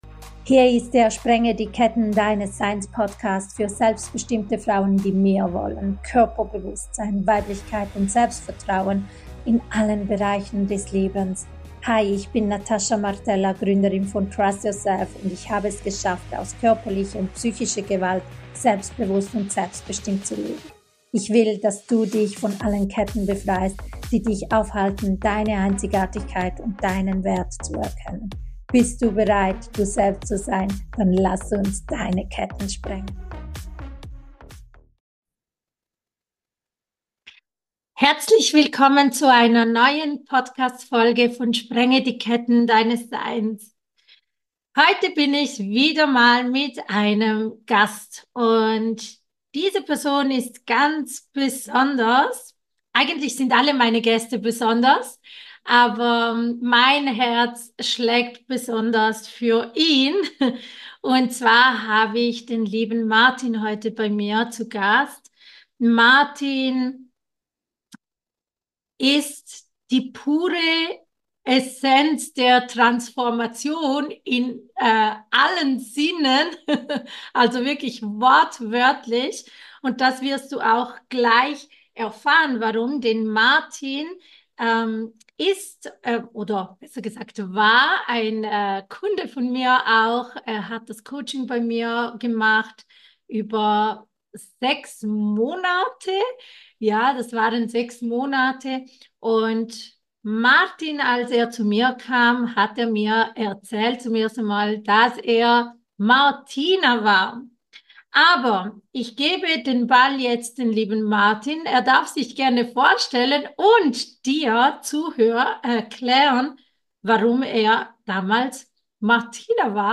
#12 Interview